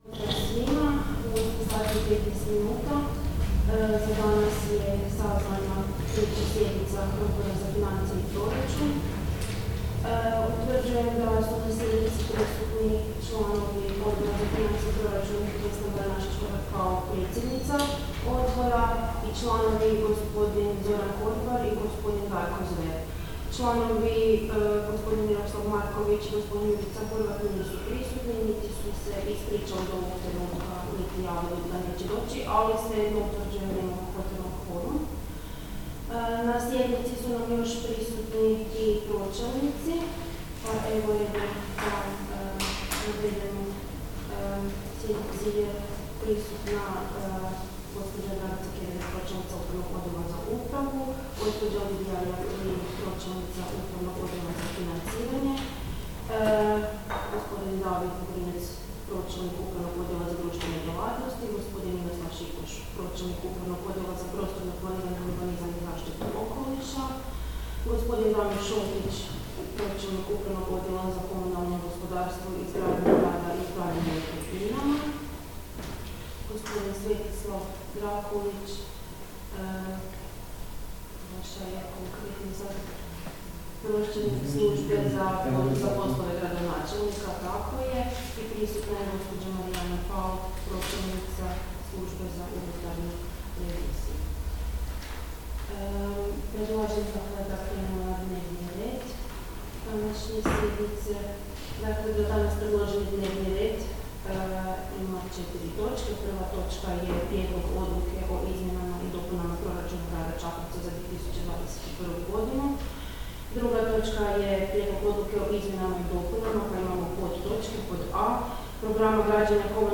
Obavještavam Vas da će se 3. sjednica Odbora za financije i proračun Gradskog vijeća Grada Čakovca održati dana 20. listopada 2021. (srijeda), u 08:15 sati, u gradskoj vijećnici Grada Čakovca.